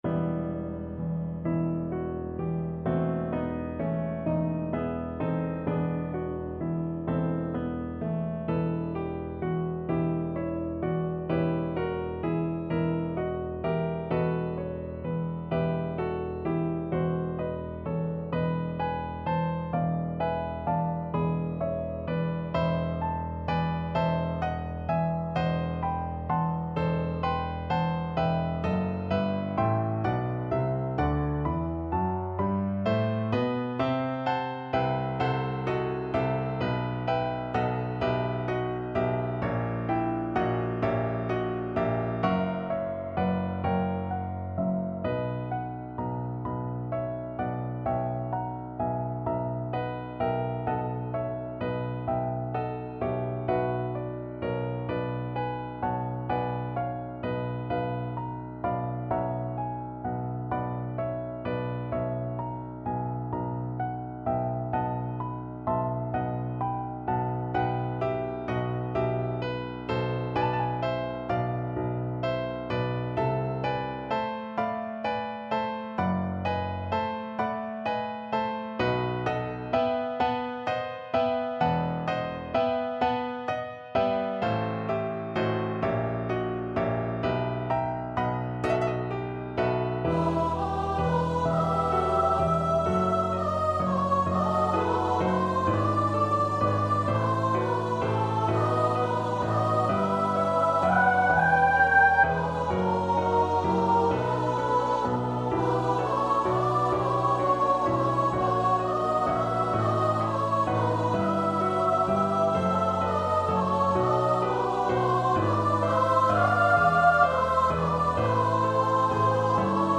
Choir  (View more Advanced Choir Music)
Classical (View more Classical Choir Music)